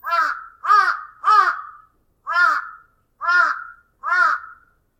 cuervo6
crow6.mp3